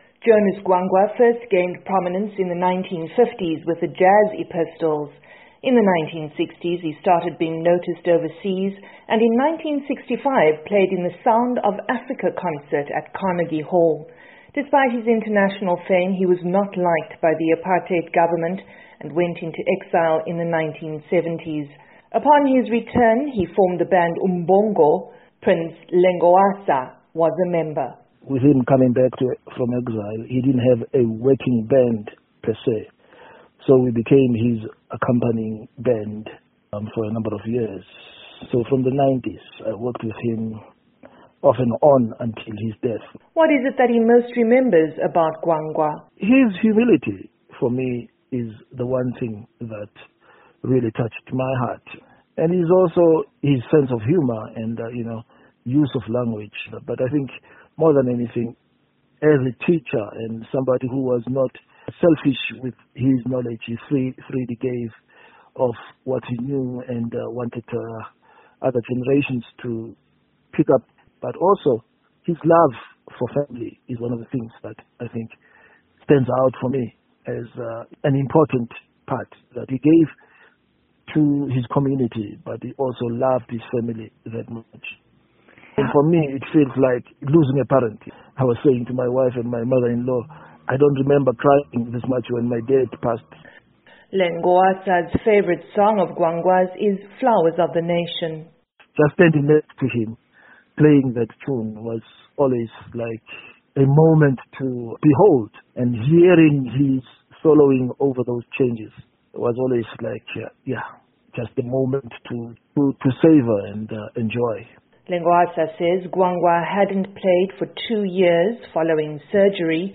Tributes are pouring in for legendary South African jazz trombonist Jonas Gwangwa who died on Saturday at age 83. For Africa News Tonight